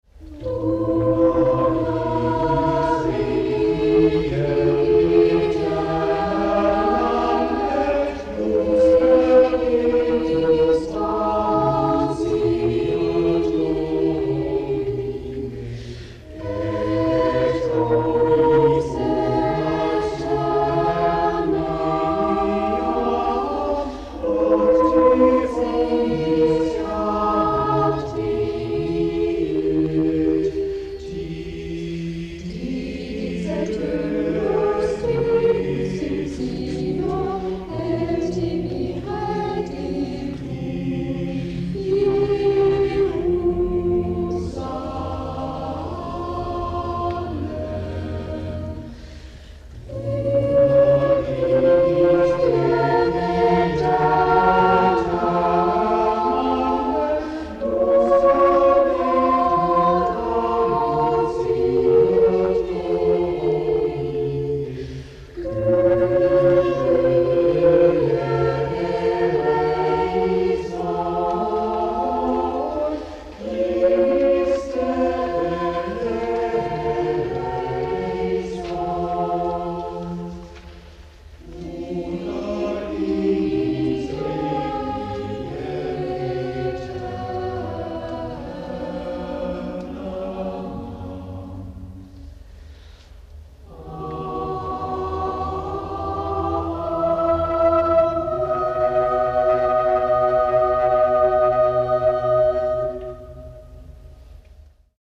die sätze für ein requiem für chor und orgel
in der laboer anker-gottes-kirche